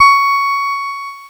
Cheese Note 19-C#4.wav